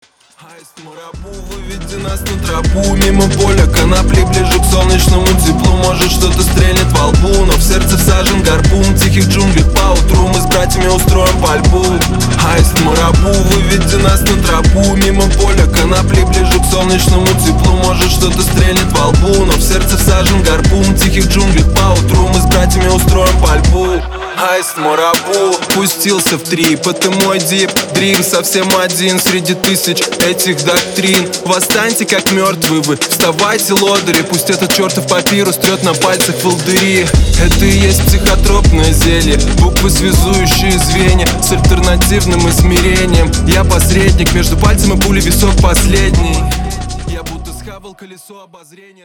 • Качество: 320, Stereo
русский рэп
drum n bass